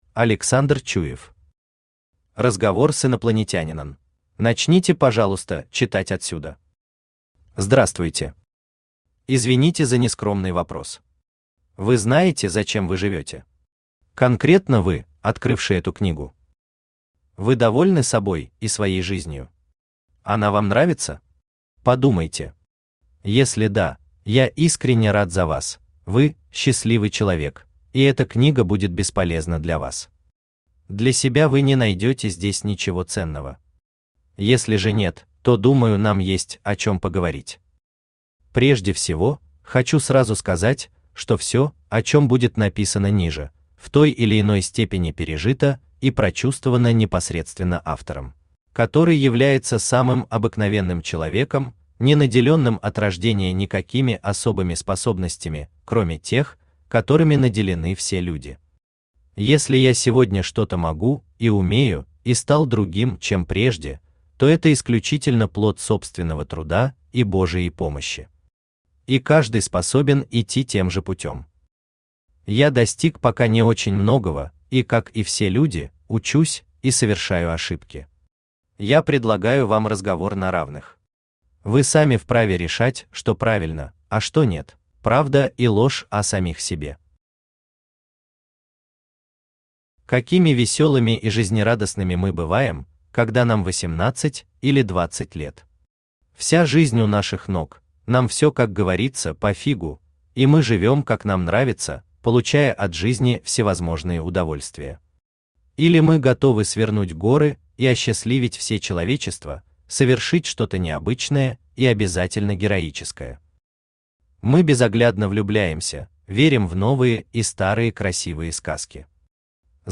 Аудиокнига Разговор с инопланетянином | Библиотека аудиокниг
Aудиокнига Разговор с инопланетянином Автор Александр Викторович Чуев Читает аудиокнигу Авточтец ЛитРес.